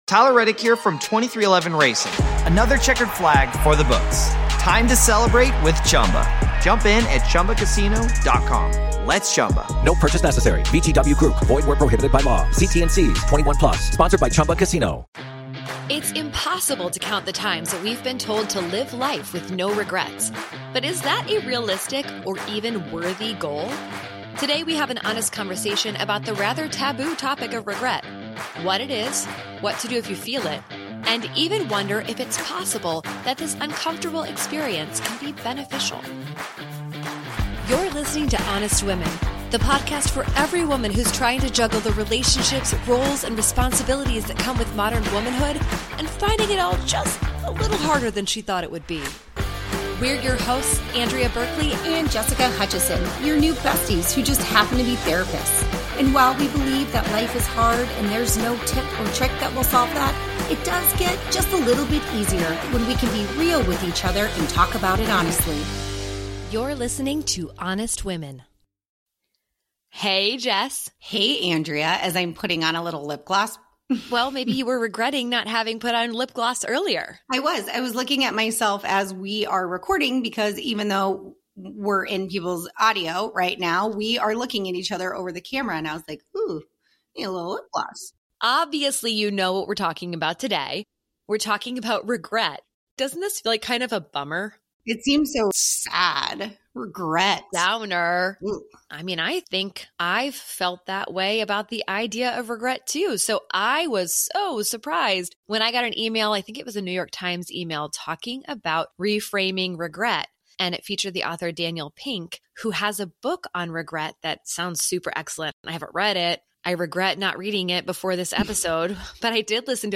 Today we have an honest conversation about the rather taboo topic of regret: what it is, what to do if you feel it, and even wonder if it is possible that this rather uncomfortable experience can be beneficial.